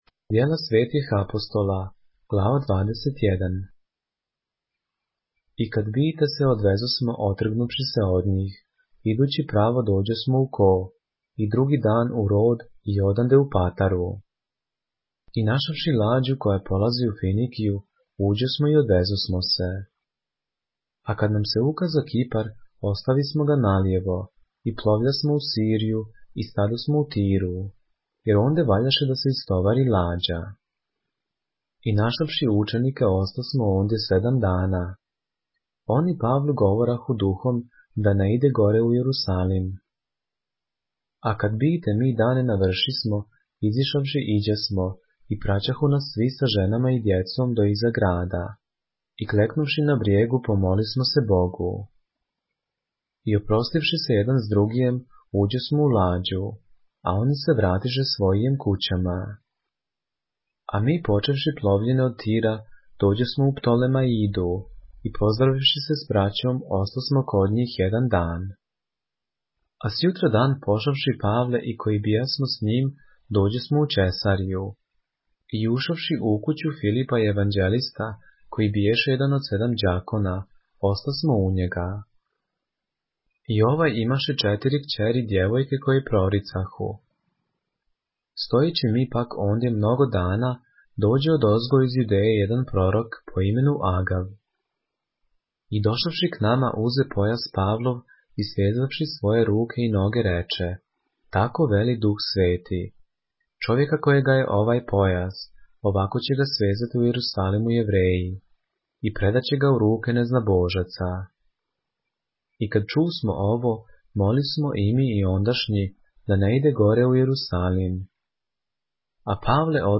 поглавље српске Библије - са аудио нарације - Acts, chapter 21 of the Holy Bible in the Serbian language